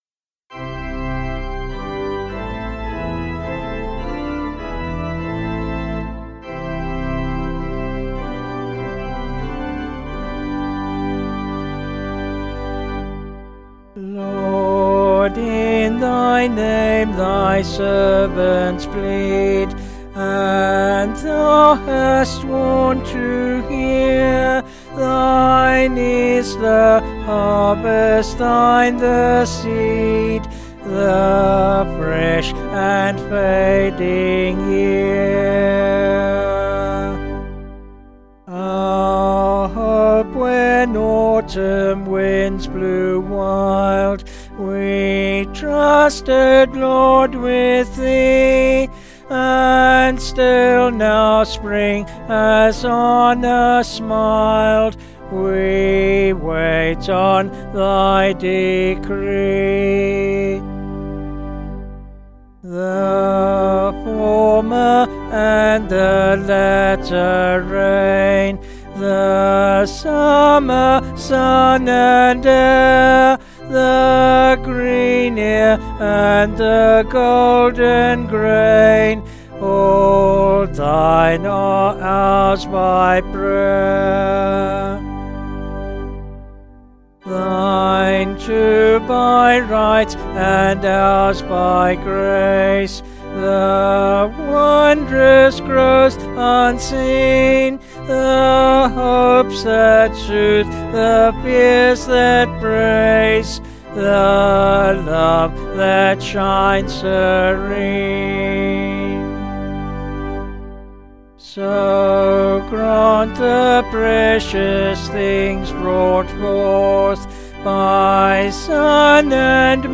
Vocals and Organ   704kb Sung Lyrics